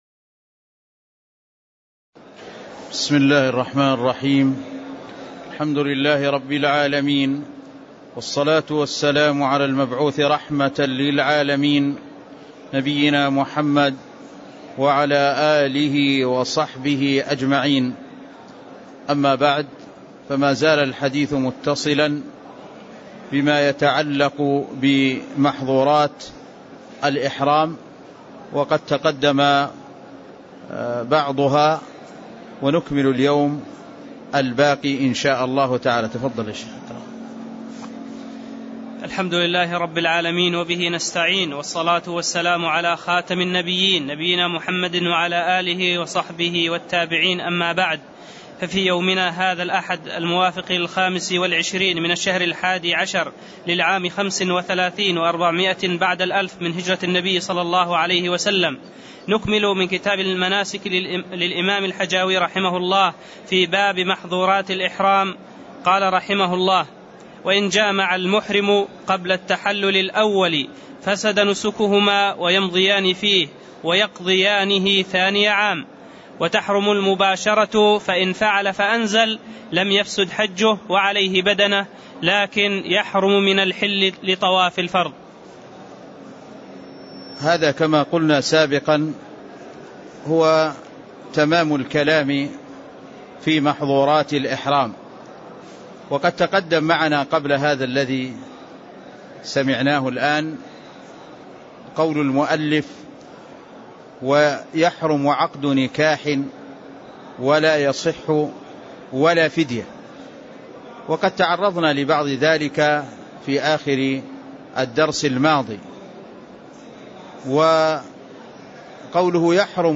تاريخ النشر ٢٥ ذو القعدة ١٤٣٥ هـ المكان: المسجد النبوي الشيخ